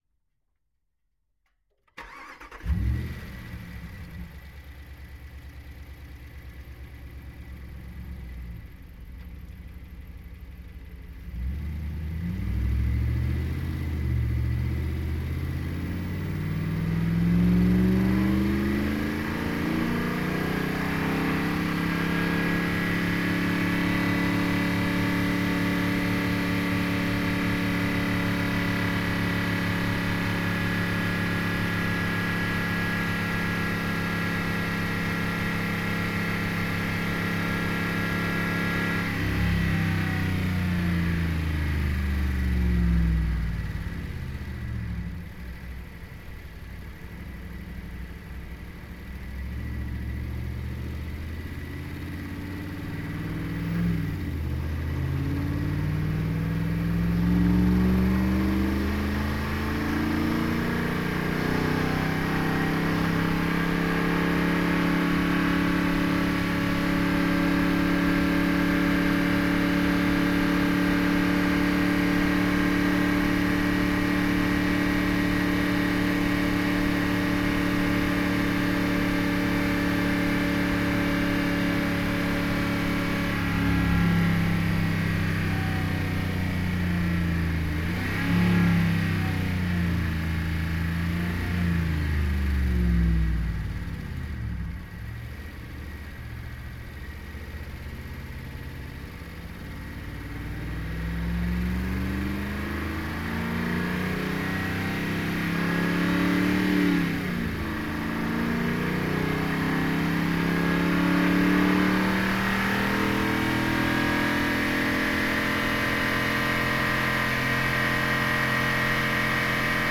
Pole Position - Dodge Ram 1500 HEMI SUV 2014
Dodge_Ram_t6_Onbrd_Start_Drive_Steady_RPMs_Engine_PZM.ogg